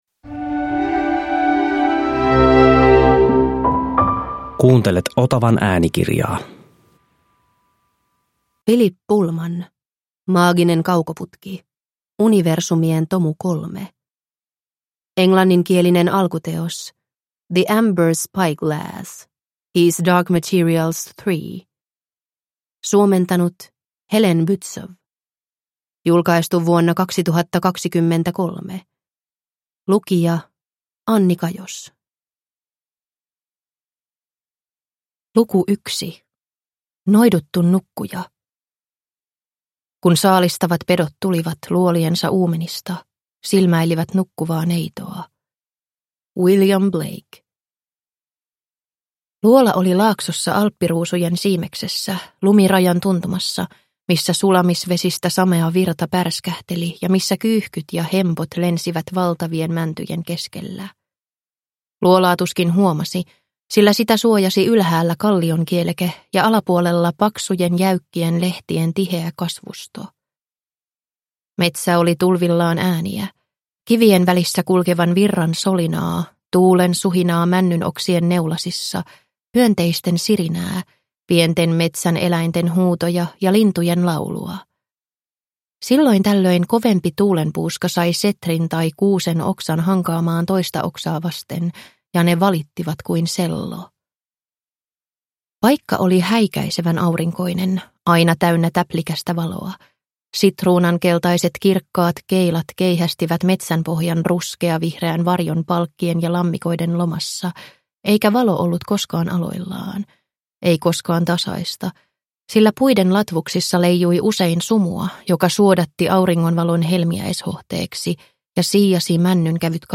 Maaginen kaukoputki – Ljudbok – Laddas ner